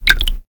Lock.ogg